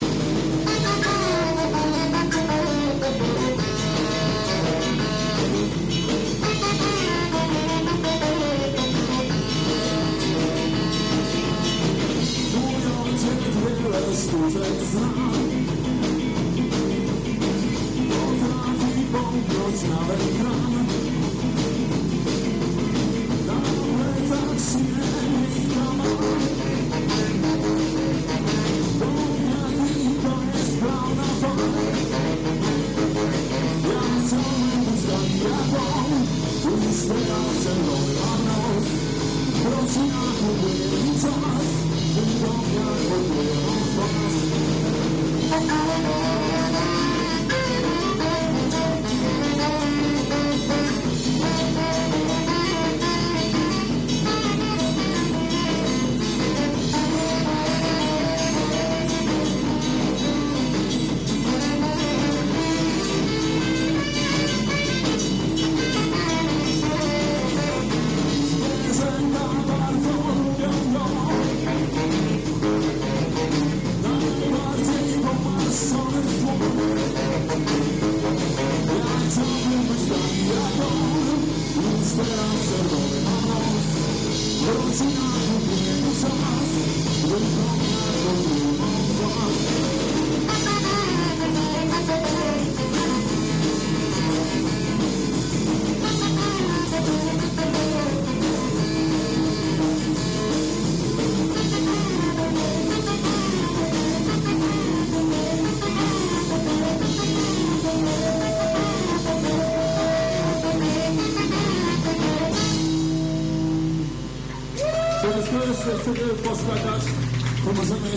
Jakosc nagran bootlegowa!
Zapraszamy do wysluchania koncertowej premiery